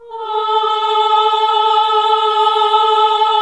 AAH A2 -L.wav